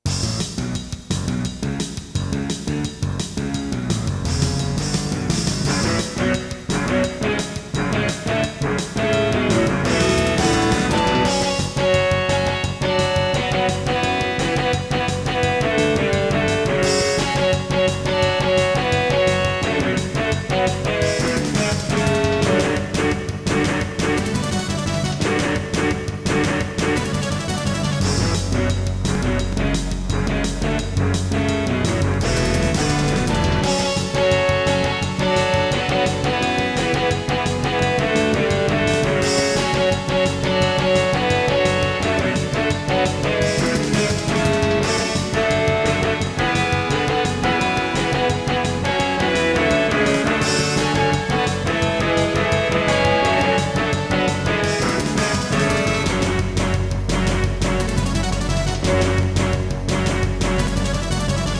* Some records contain clicks.